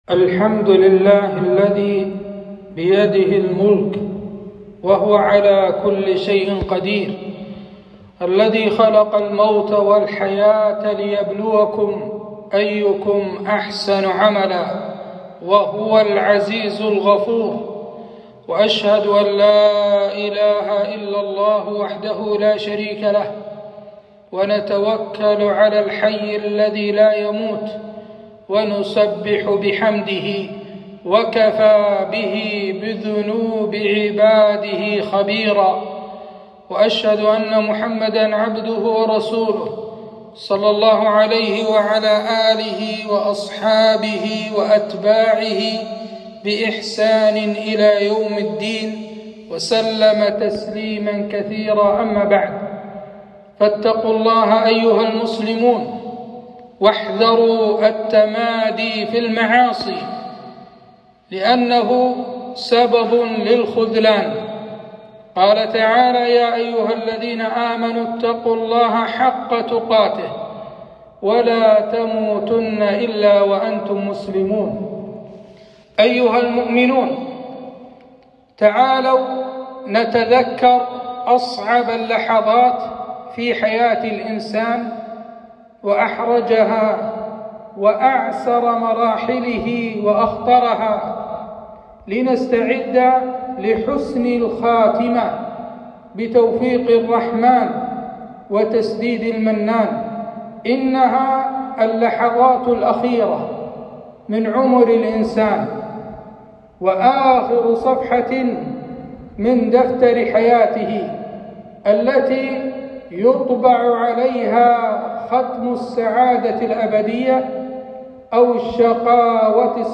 خطبة - أسباب حسن الخاتمة